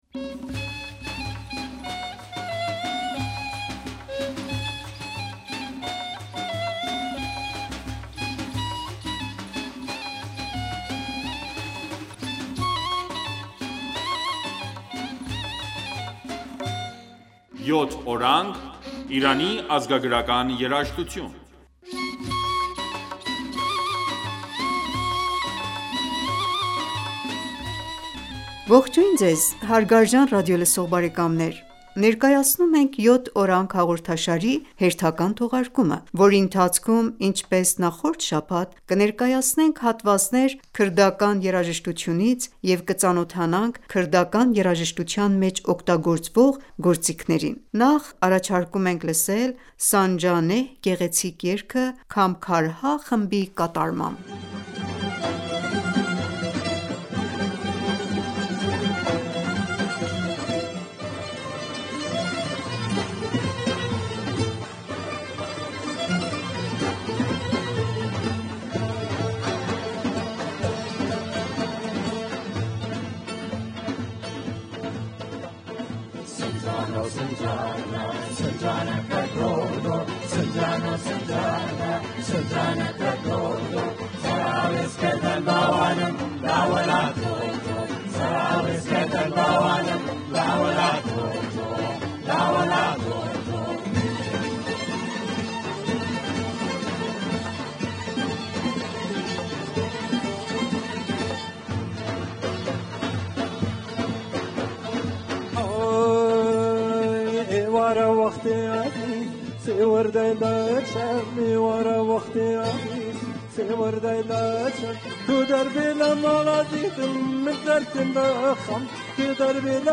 Ողջույն Ձեզ հարգարժան ռադիոլսող բարեկամներ։ Ներկայացնում ենք «Յոթ օրանգ» հաղորդաշարի հերթական թողարկումը,որի ընթացքում ինչպես նախորդ շաբաթ կներկայացնենք...